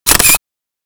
shutter.wav